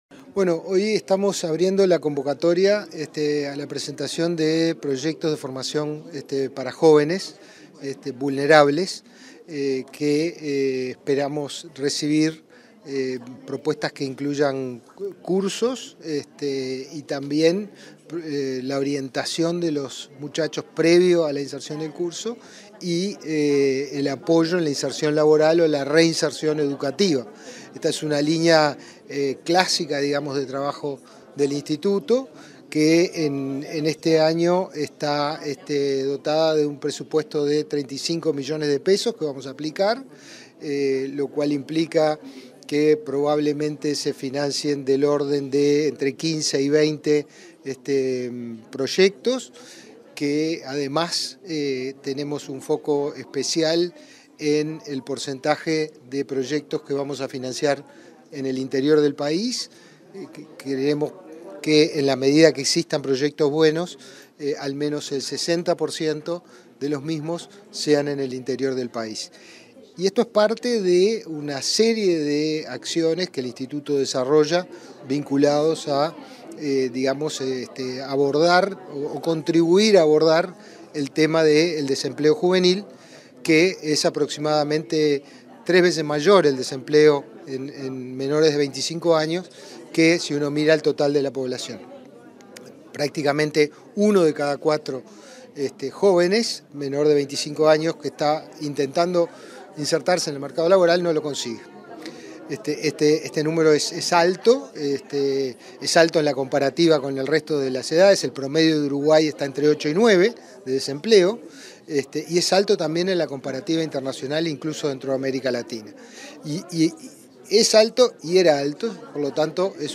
Entrevista al director del Inefop, Pablo Darscht
El Instituto Nacional de Empleo y Formación Profesional (Inefop) y el Instituto Nacional de la Juventud (INJU) lanzaron, este 22 de agosto, una convocatoria a proyectos de formación profesional que estén focalizados en jóvenes de entre 15 y 24 años. En la oportunidad, el director de Inefop, Pablo Darscht, realizó declaraciones a Comunicación Presidencial.
inefop entrevista.mp3